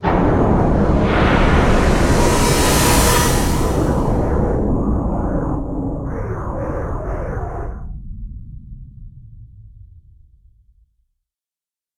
Портал с темной магией распахнул врата